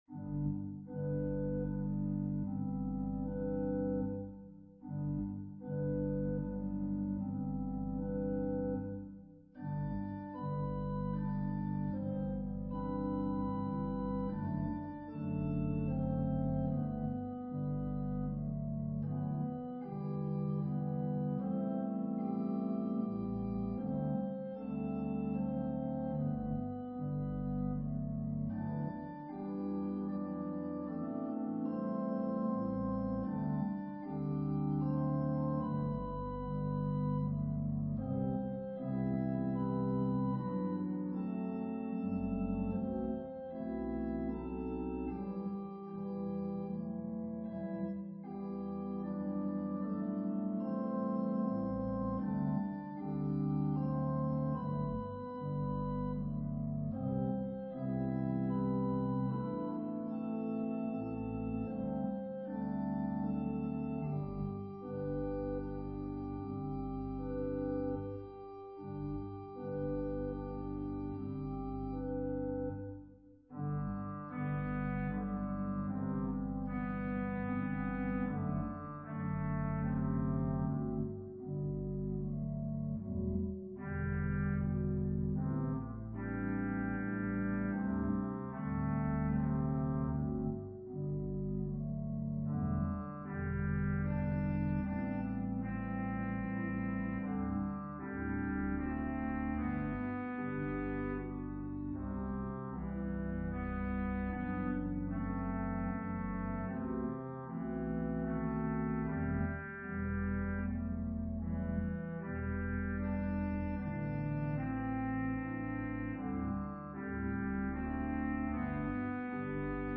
An organ solo version
Voicing/Instrumentation: Organ/Organ Accompaniment